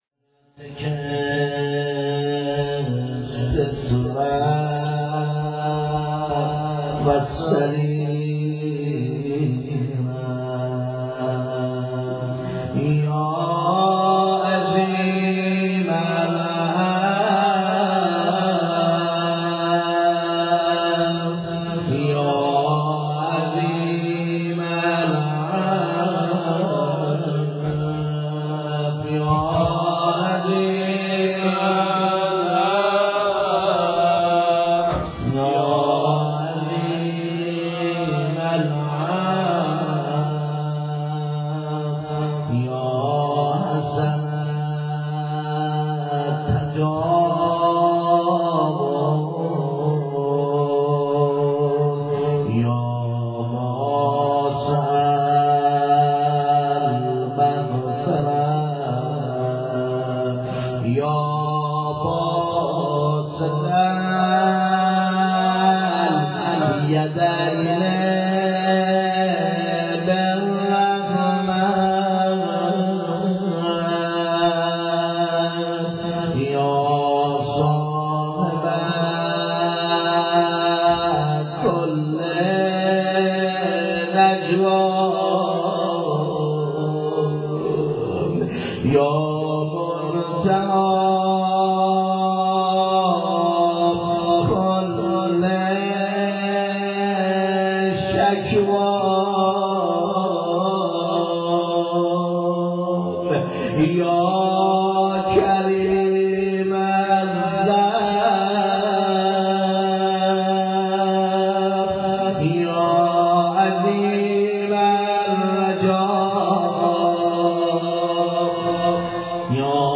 rozeh.wma